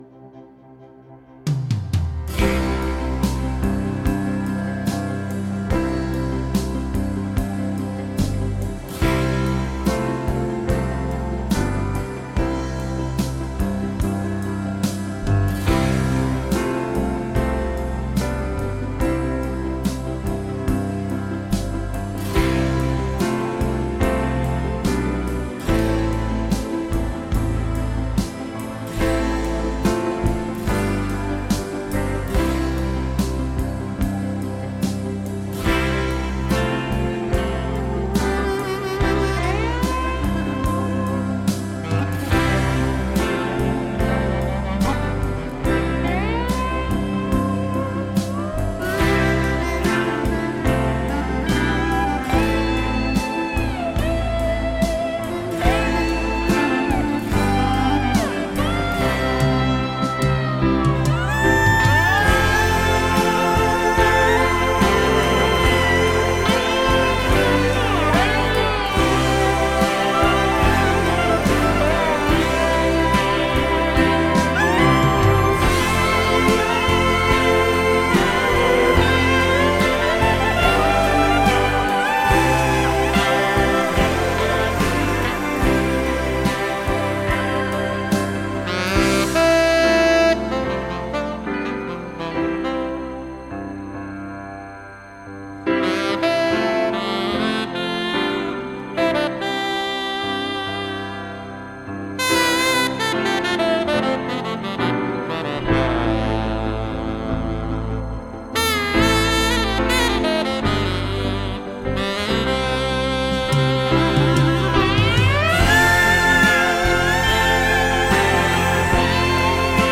По спектру видно, что высокие грубо порезаны на 16 кгц, вокал до 5 кгц, 2 отрезка с инструментами до 10кгц - это не испорчено.
Битрейт(скорость потока) 320, а сигнал 16 кгц! Все!
Спектр говорит, что сигнал обрезали и надо искать лучший.